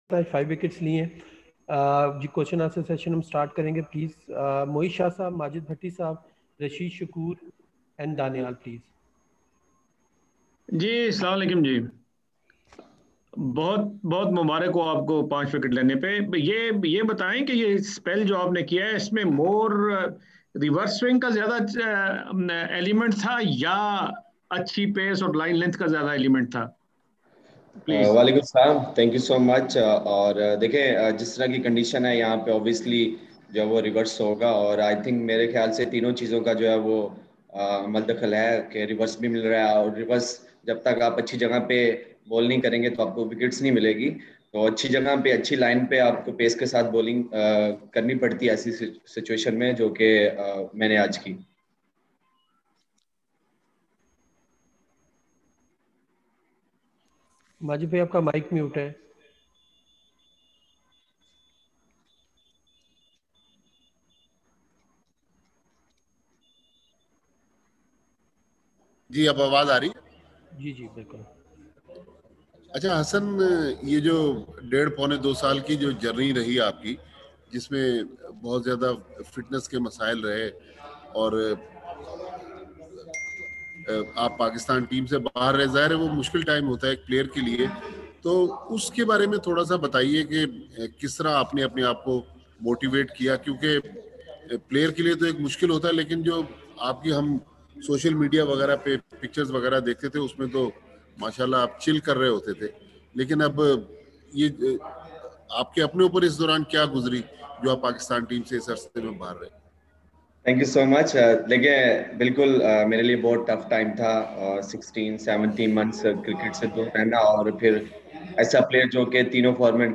After stumps, Pakistan’s Hasan Ali and South Africa’s George Linde held online virtual media conferences.